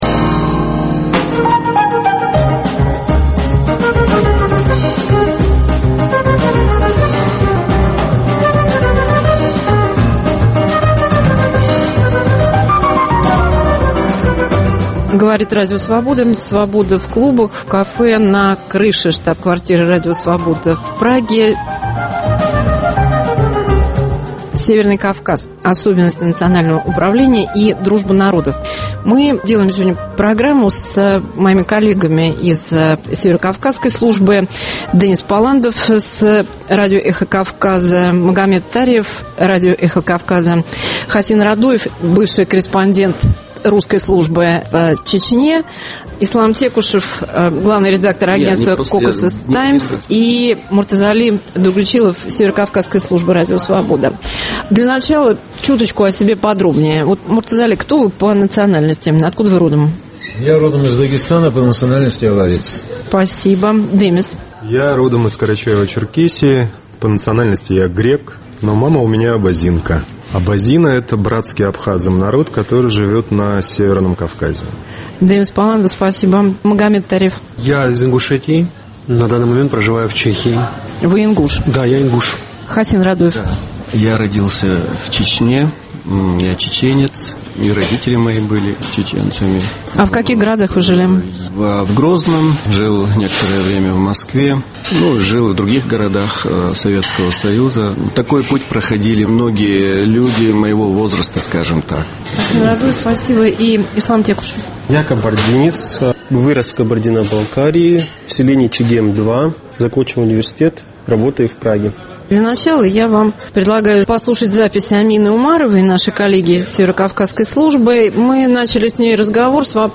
"Cвобода в клубах" в кафе на крыше штаб-квартиры Радио Свобода в Праге. Северный Кавказ: особенности национального управления и "дружба народов". Почему северокавказские народы в составе России не могут чувствовать себя россиянами?